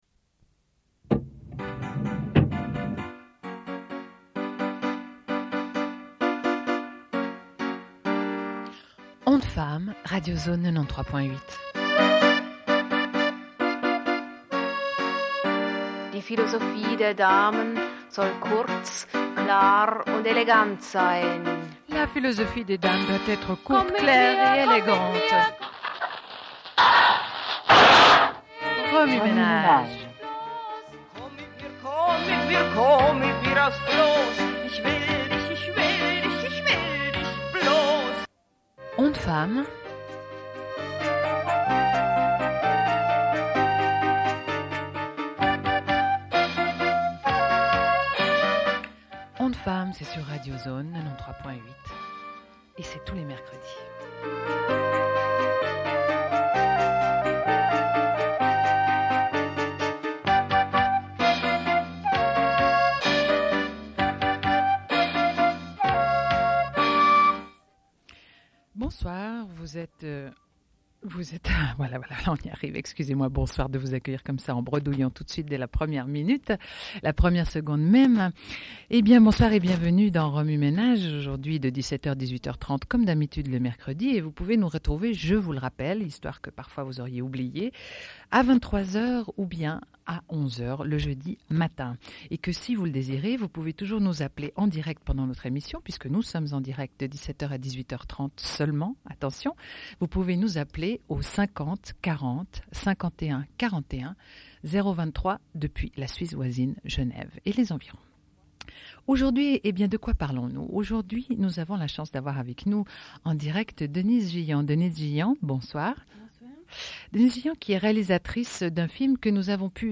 Une cassette audio, face A31:30